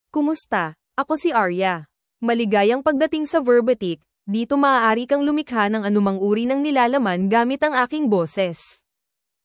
FemaleFilipino (Philippines)
AriaFemale Filipino AI voice
Voice sample
Listen to Aria's female Filipino voice.
Aria delivers clear pronunciation with authentic Philippines Filipino intonation, making your content sound professionally produced.